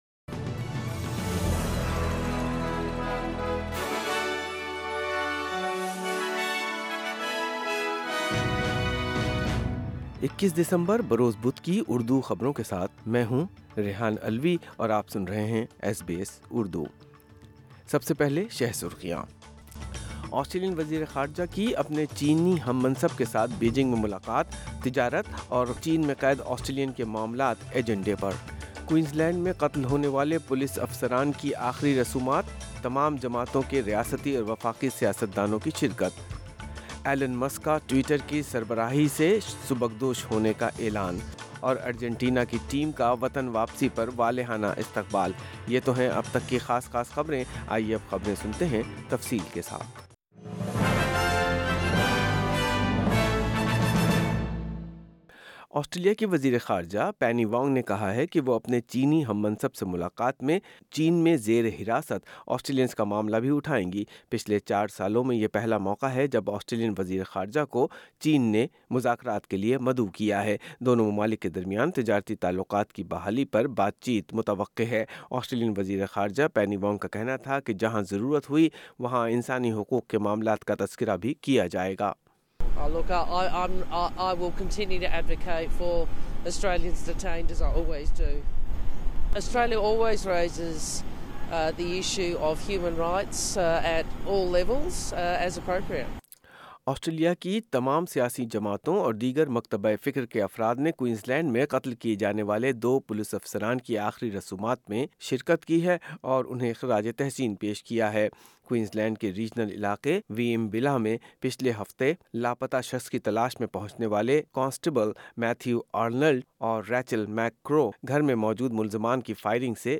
Urdu News Wednesday 21 December 2022